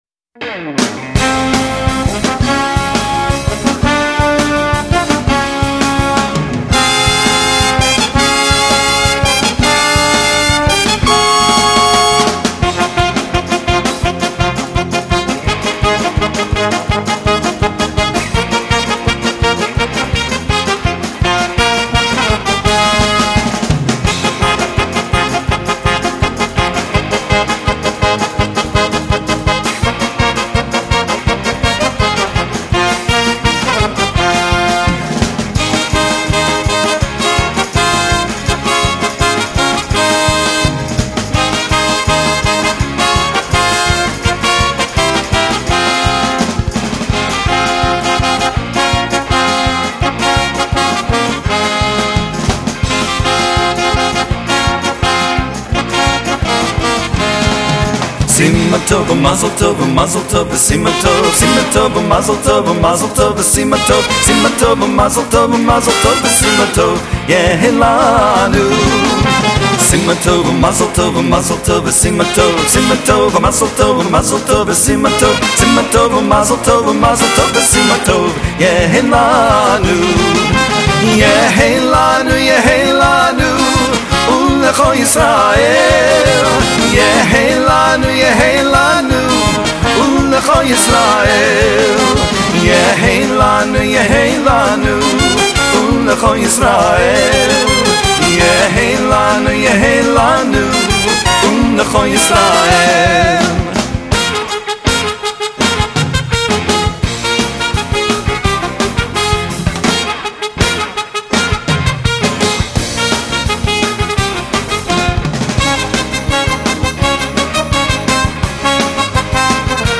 Traditional Hora Dance